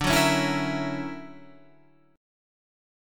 D Augmented Major 9th